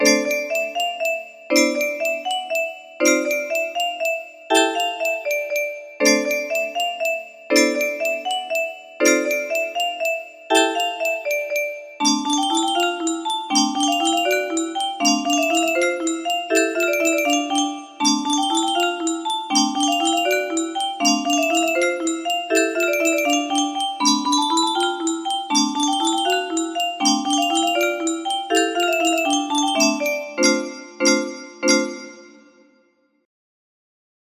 Full range 60
the end is crappy but whatever